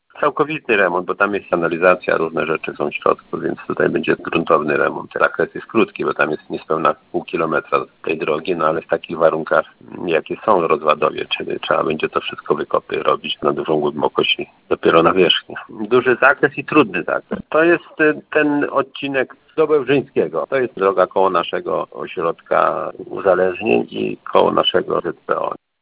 Mówi starosta stalowowolski Janusz Zarzeczny: